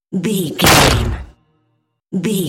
Dramatic hit bone
Sound Effects
heavy
intense
dark
aggressive
the trailer effect